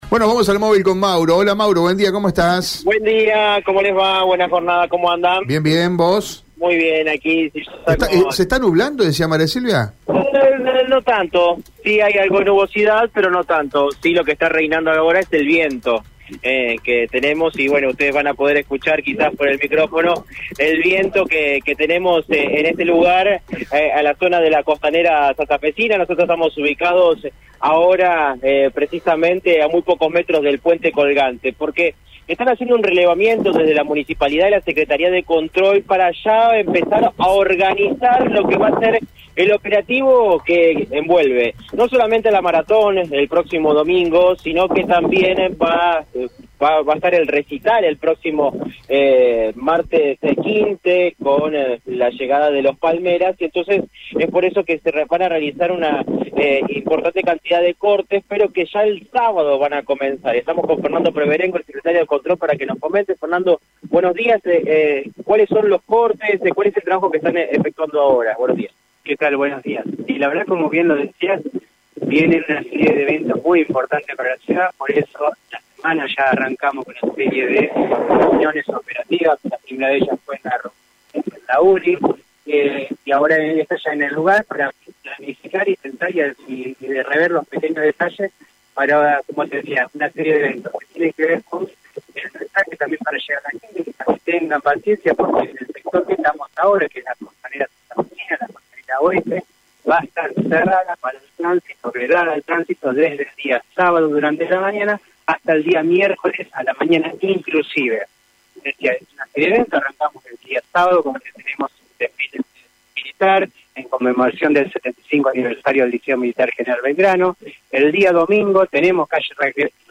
En diálogo con el móvil de Radio EME, Fernando Peverengo, secretario de Control de la Municipalidad de Santa Fe, detalló las acciones que llevarán adelante: «La fiesta de la ciudad de Santa Fe tendrá mucha concurrencia, por eso estamos trabajando con la policía».